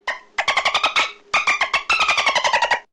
Звуки запикивания мата
Гул локомотива или гудок поезда